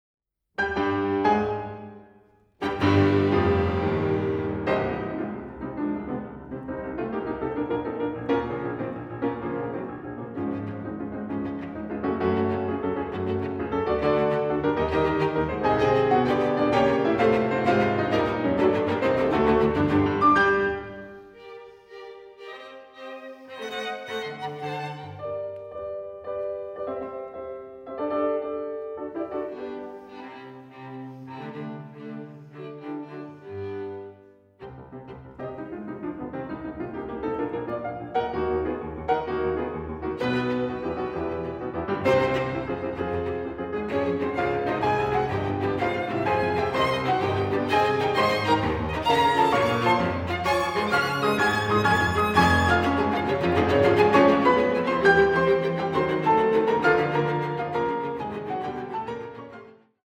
Kammermusik zwischen Romantik und Exil